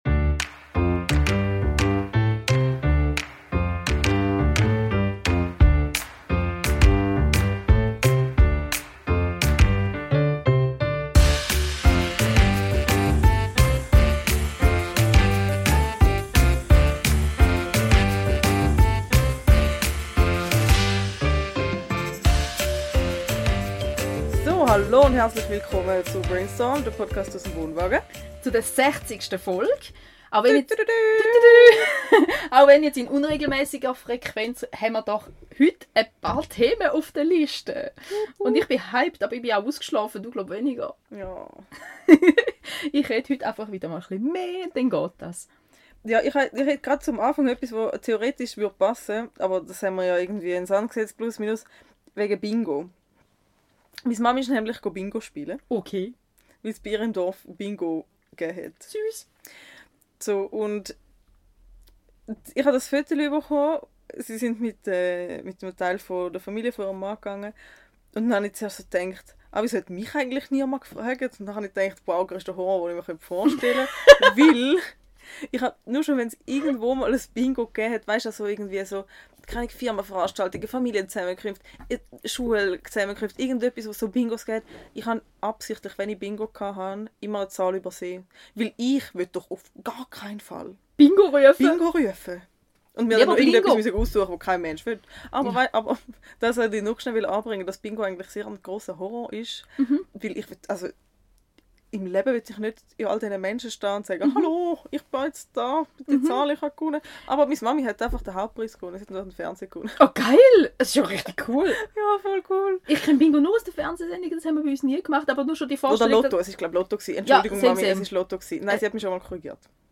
Heute direkt aus dem Dinomuseum sind wir endlich wieder im Wohnwagen! Hier behandeln wir mehr shitshow Themen als vorgenommen aber heben auch dir Heldin der Woche hervor.